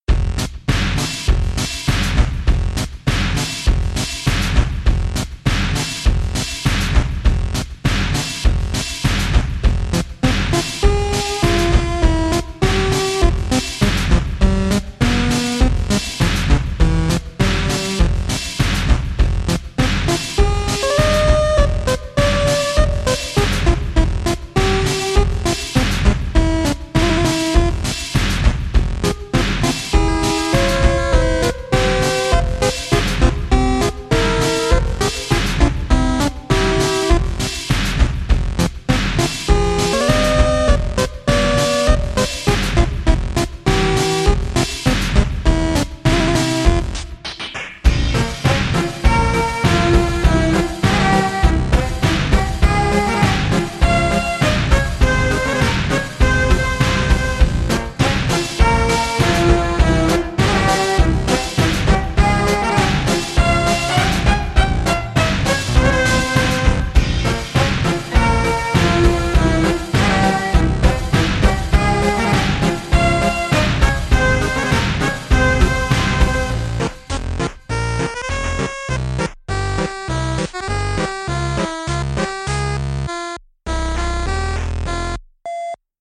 slowed it down to x0.666
This is a lot more ominous slowed down.